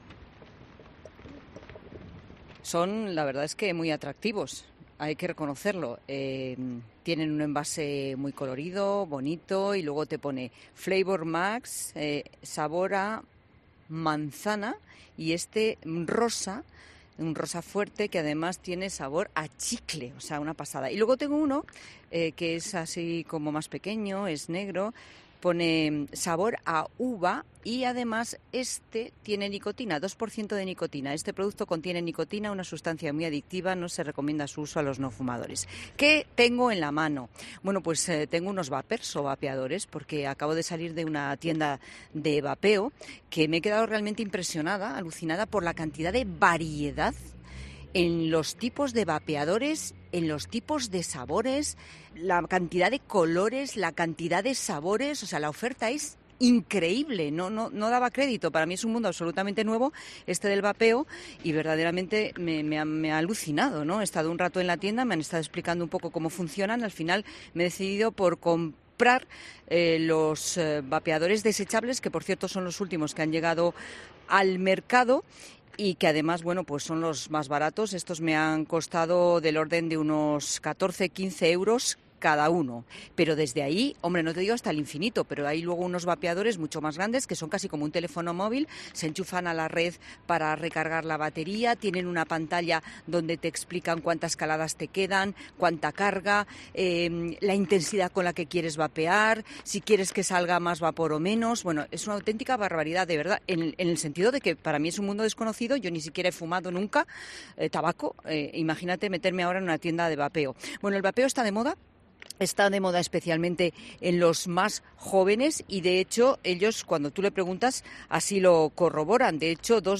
Escucha ya el reportaje completo reproduciendo el siguiente audio: